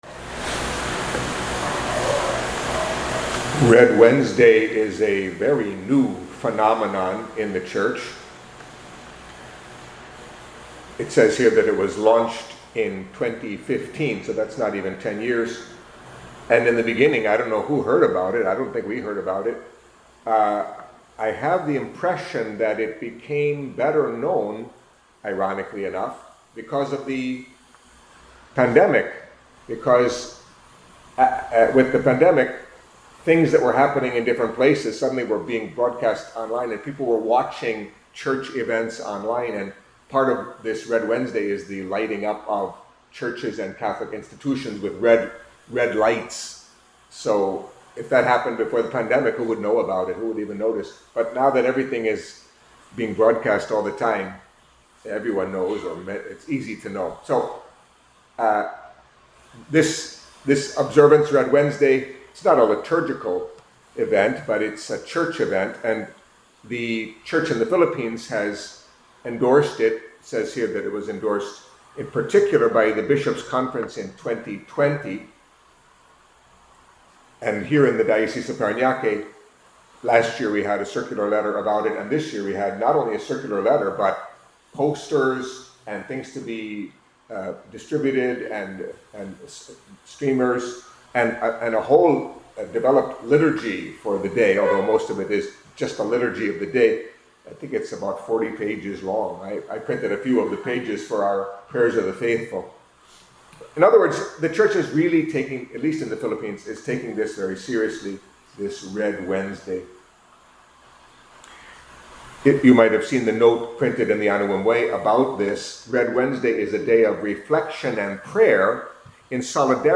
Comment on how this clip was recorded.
Catholic Mass homily for Wednesday of the Thirty-Fourth Week in Ordinary Time